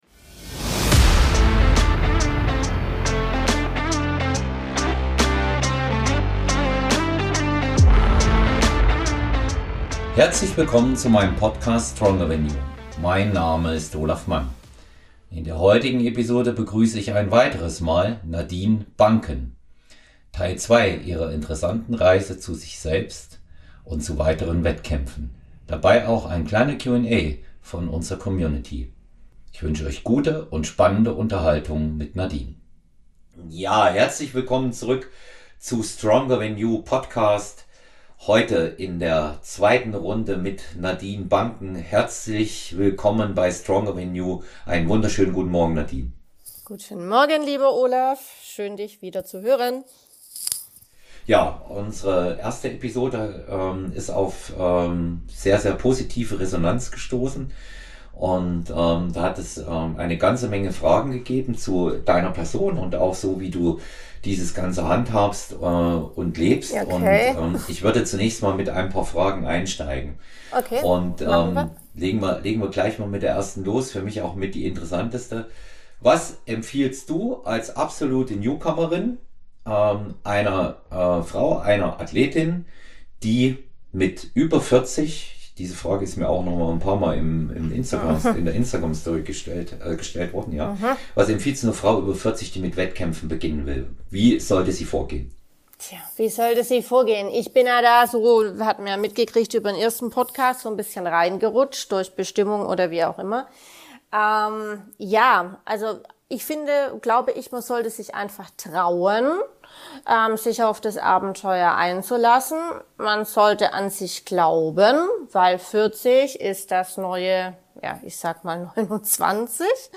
Die Gespräche drehen sich um Natural Bodybuilding, Kampfsport, Sport im Allgemeinen, Ernährung und gelegentlich ein wenig Psychologie, eben alles rund um einen gesunden Lebensstil.